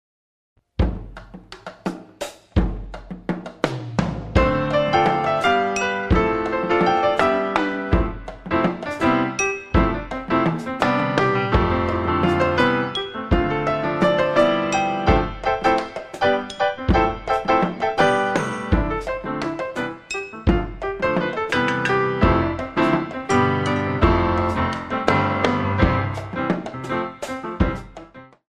The Original PIANO Compositions
good for ballet and modern lyrical